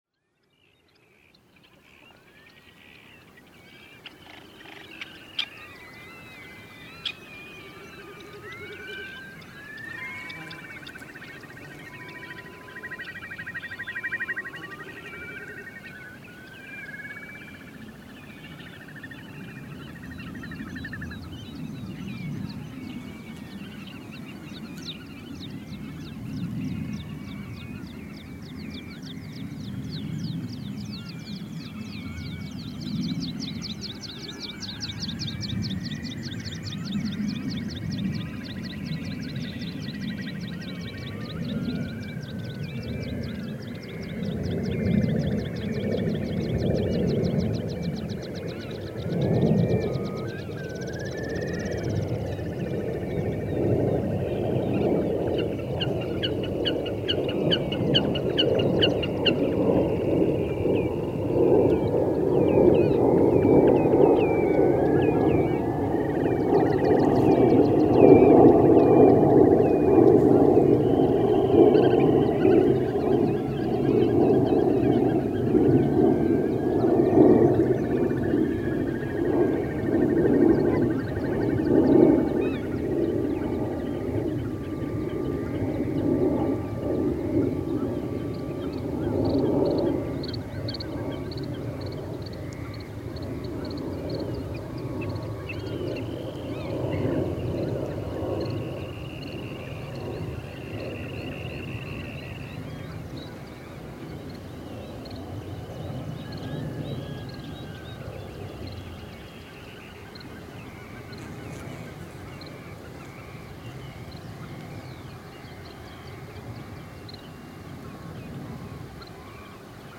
This recording was captured around 4:00 to 5:00 am, 25th of June. It is almost straight continue of part 3 with very quiet relaxing midsummer sound scape but now disturbed with two airplanes passing by from east to west.
During calm moments thick clouds of gnats filled the air with heavy buzzing noise, but they got suddenly quiet every time when the light gusts stroke the field. Quality headphone recommended.
Hér má heyra í fuglum s.s. óðinshana, hrossagauk, stelk, lómi, spóa, kríu, hettumáfi, músarindli og maríuerlu sem og mörgum öðrum fuglum.
Tvær millilandaþotur rjúfa þögnina í þessu hljóðriti.